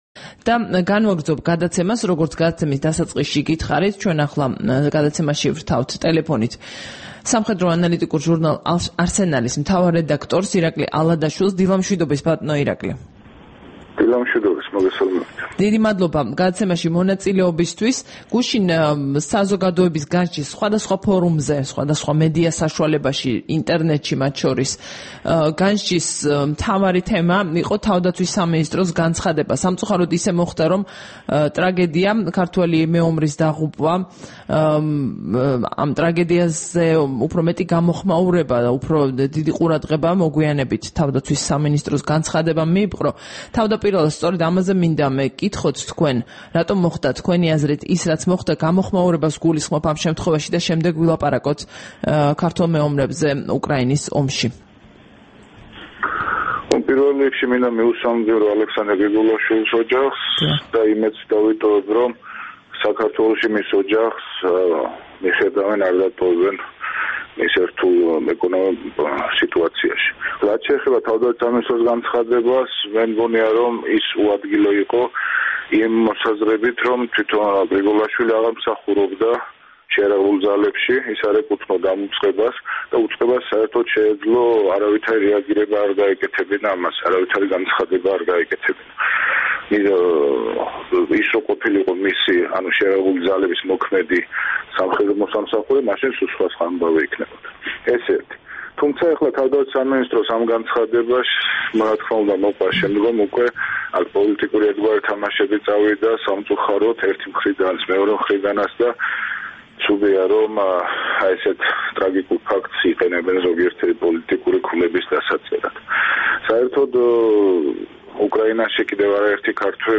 22 დეკემბერს რადიო თავისუფლების დილის გადაცემაში ჩართვისას